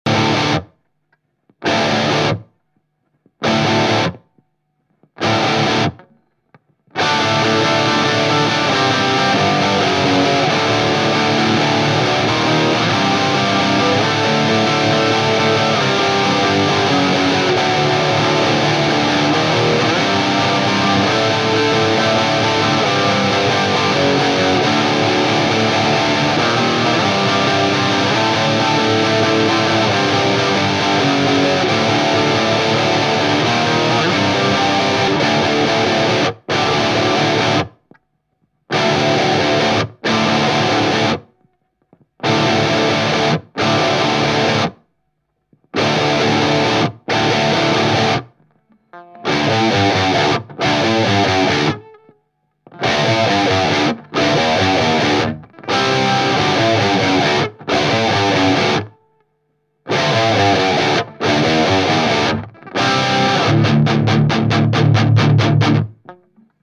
Nahrávací studio v Lipově audio / digital
Spolu s TripleXXX a dalšími "studiovými fígly" celková nahrávka HRAJE !!!